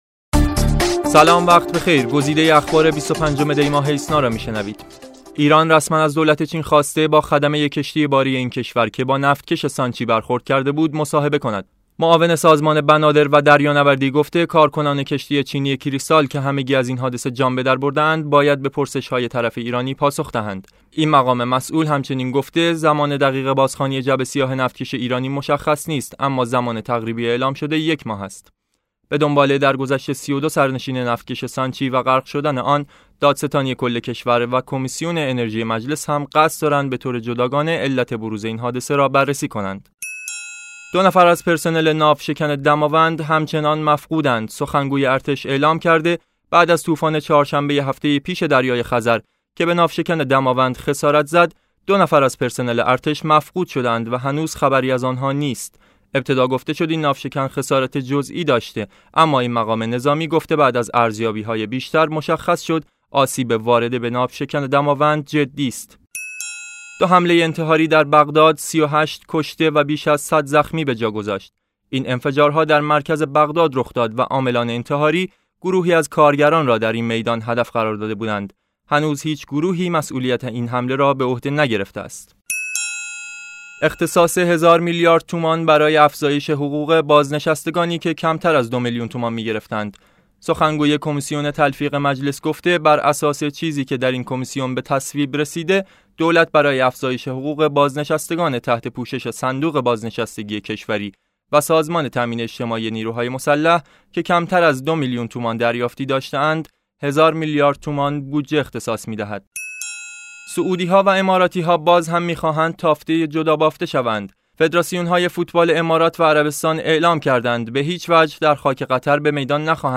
صوت / بسته خبری ۲۵ دی ۹۶